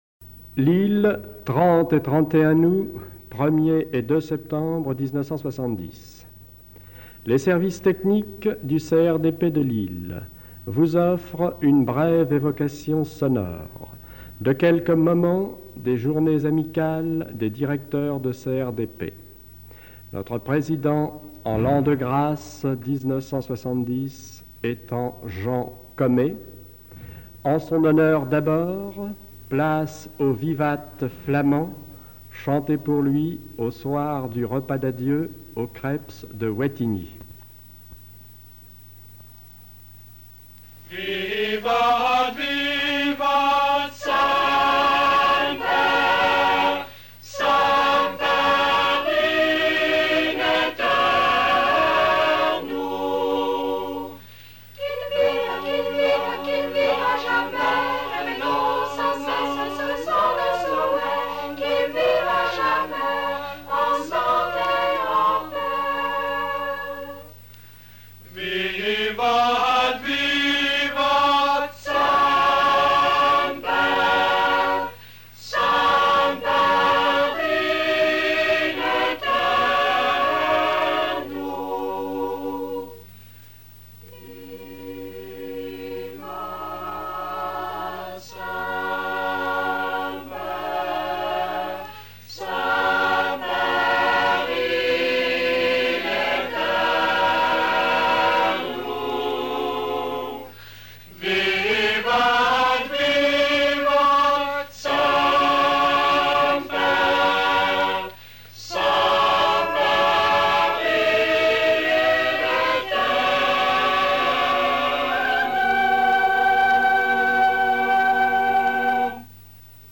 réception, inauguration
Témoignage